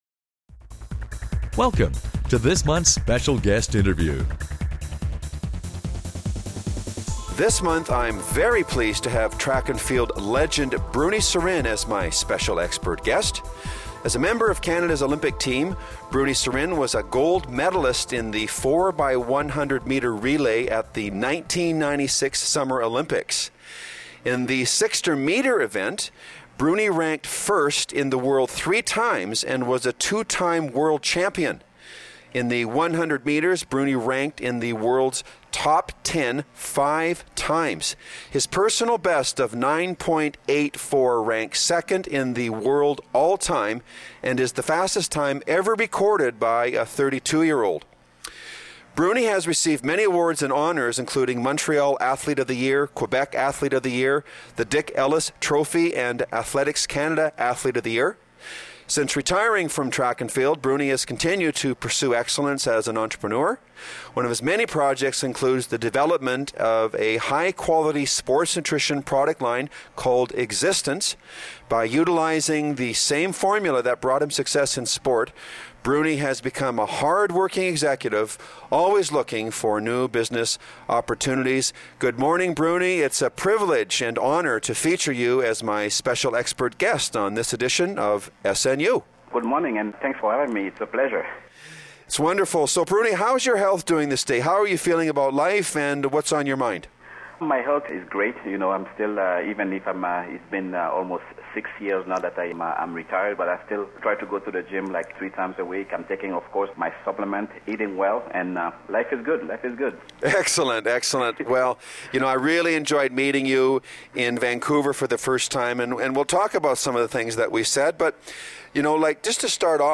Special Guest Interview Volume 6 Number 11 V6N11c - Guest As a member of Canada's Olympic team, Bruny Surin was a gold medalist in the 4x100 m relay at the 1996 Summer Olympics.